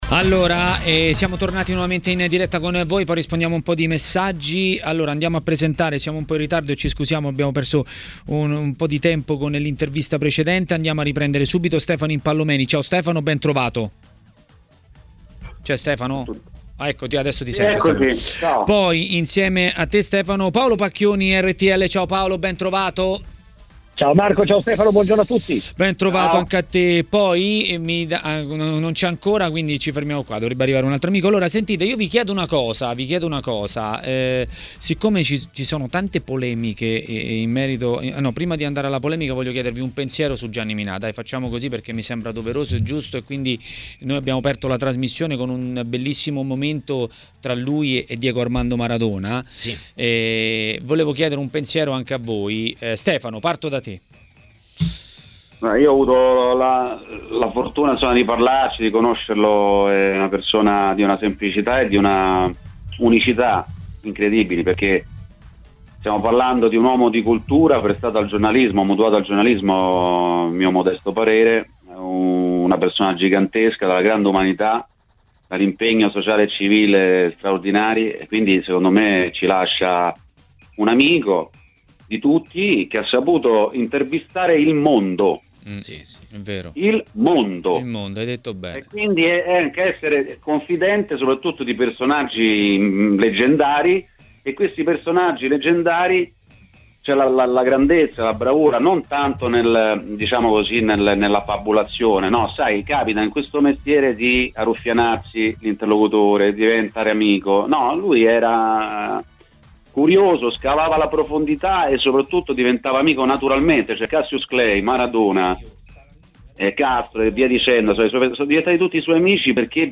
A intervenire in diretta a Maracanà, trasmissione di TMW Radio, per parlare dei temi del giorno